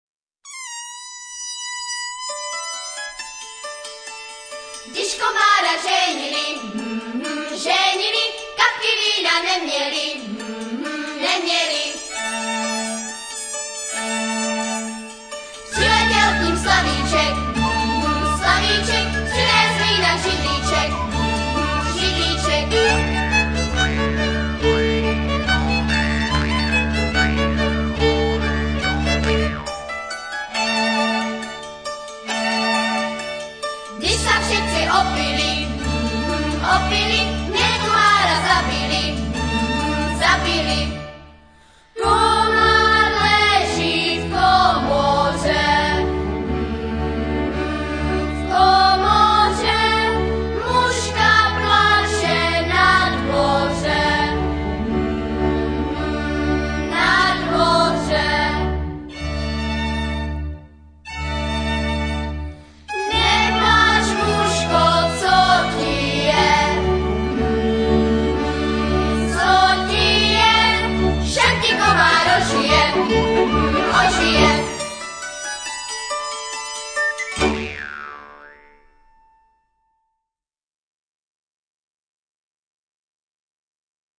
Dětské písničky z Moravy
Rozverná z Valašska